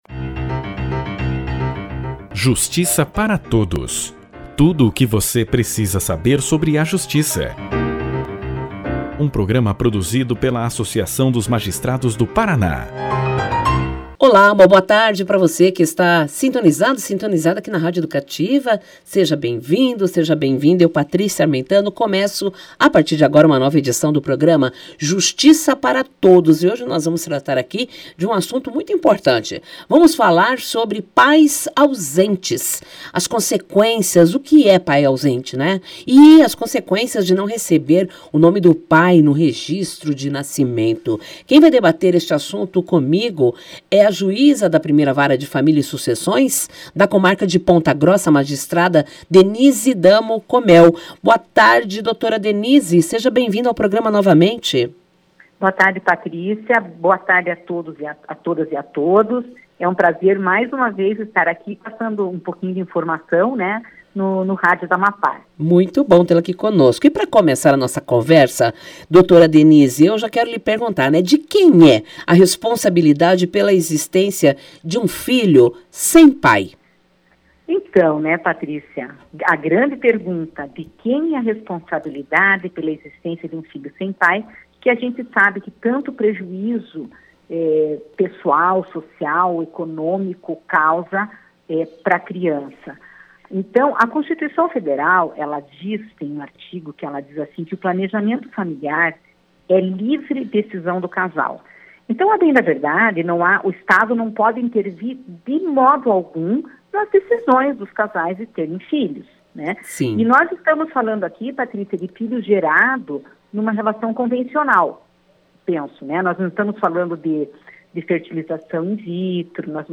O programa de rádio da AMAPAR, Justiça para Todos, entrevistou a juíza Denise Damo Comel, que atua na 1ª Vara de Família de Ponta Grossa. Na conversa a magistrada comentou questões relacionadas às consequências de pais ausentes.